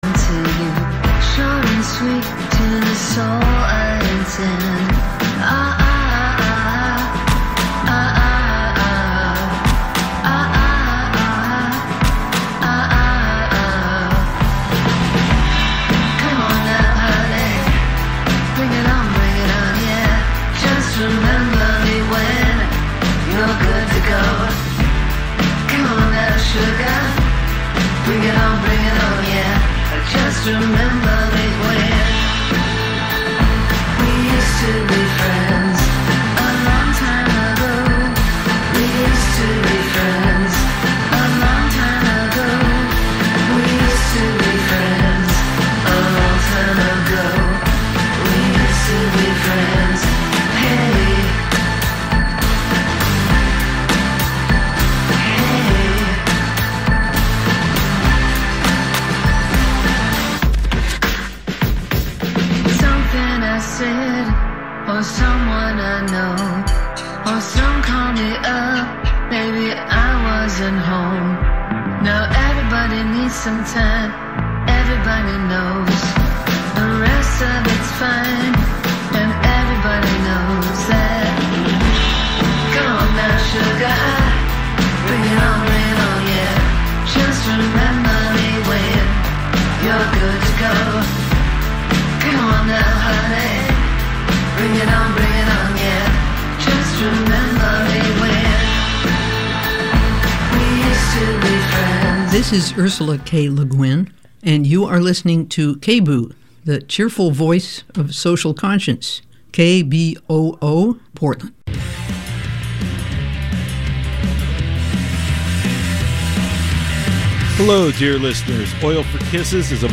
Live.